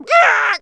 CHICKEN 2.WAV